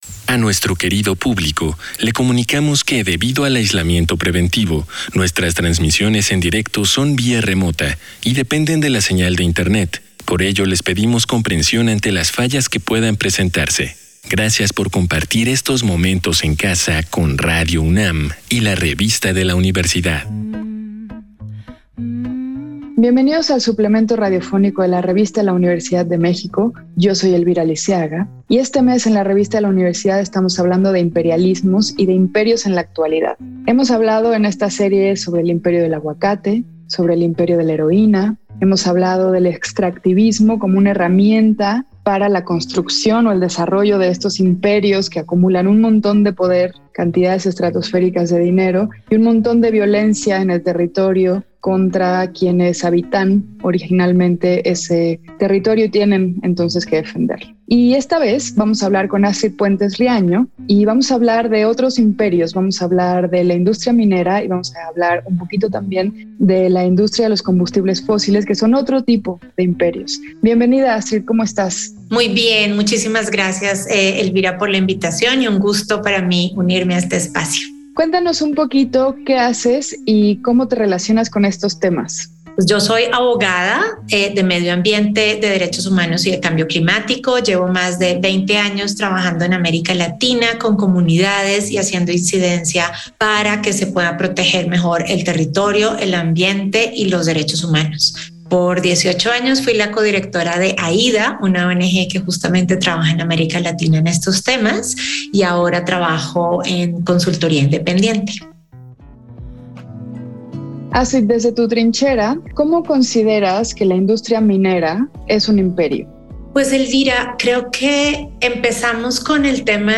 Fue transmitido el jueves 25 de noviembre de 2021 por el 96.1 FM.